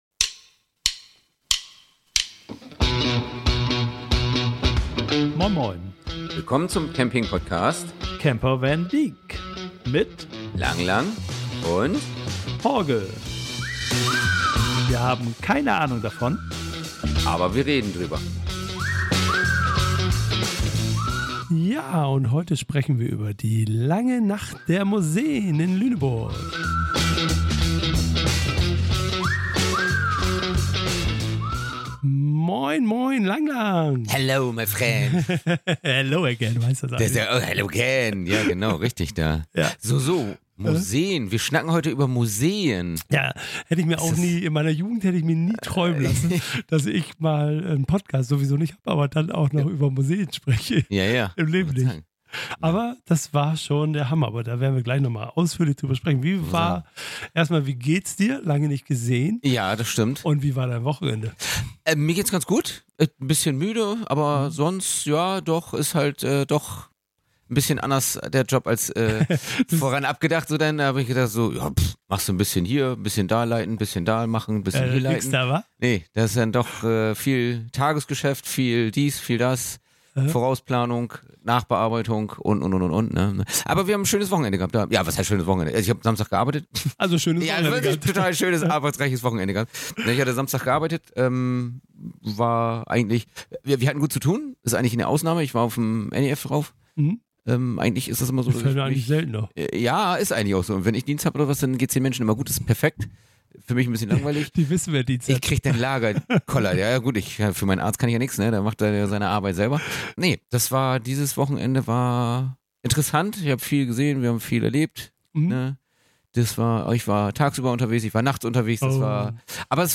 In dieser Episode nehmen wir Euch mit zu der langen Nacht der Museen in Lüneburg. Wir berichten über unsere Erlebnisse und Eindrücke, während wir die Museen durchstreifen.